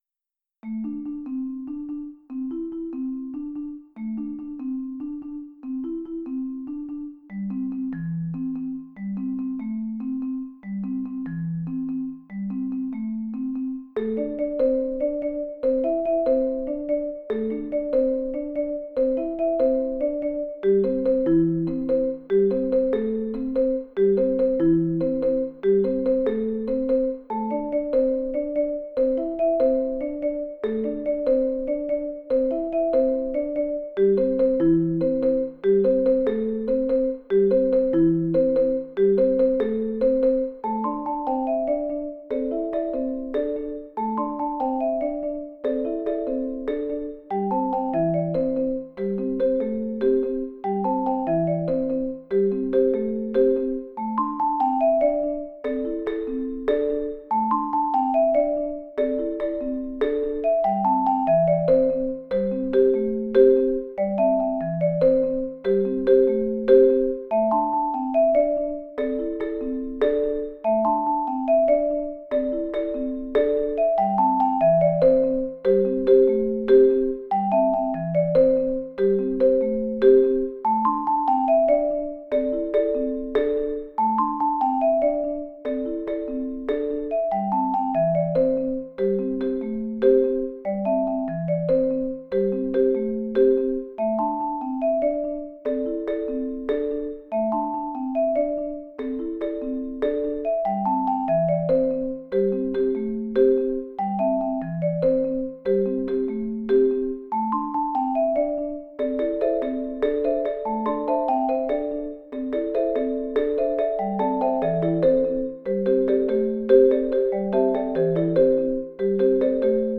MIDI audio of F24 Roadmap
We begin with a repetitive bass line, then four players move to a melodic, but syncopated, theme.
Eventually the piece fades to a quiet ending.